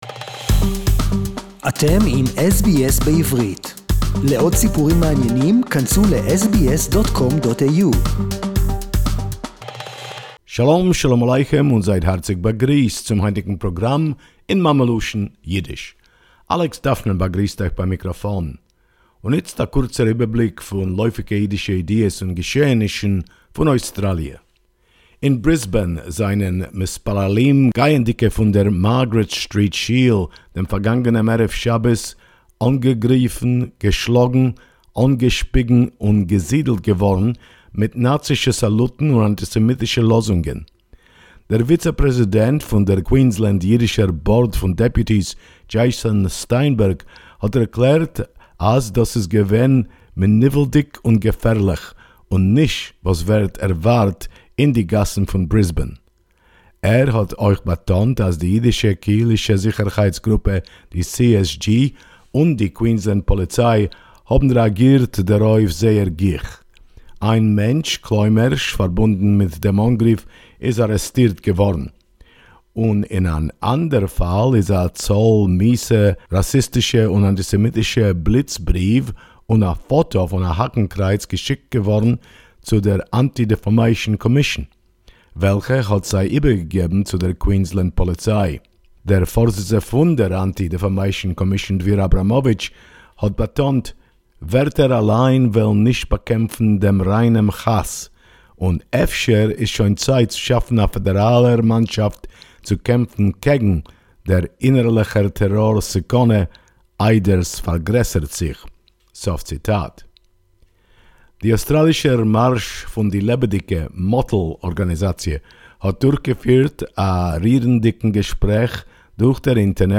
Jewish worshippers in Brisbane were attacked...SBS Yiddish report 21.2.2021